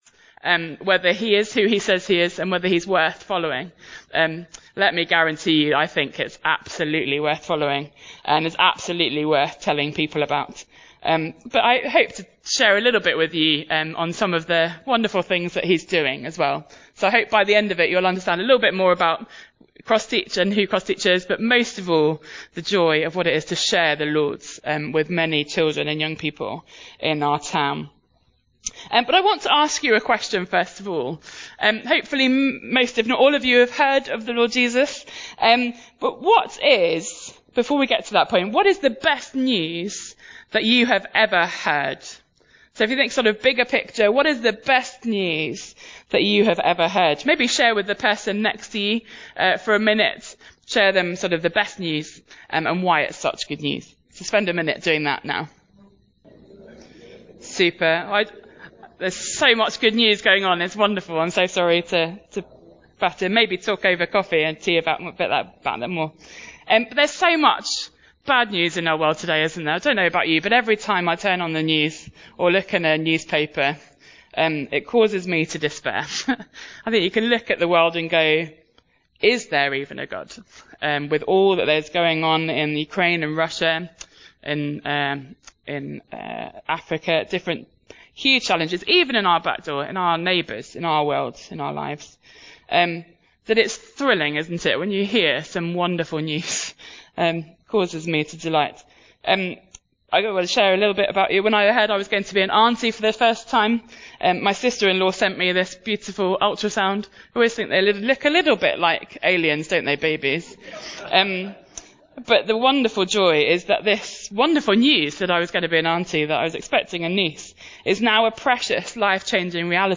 Crossteach - Telling the next generation Sermon Search media library...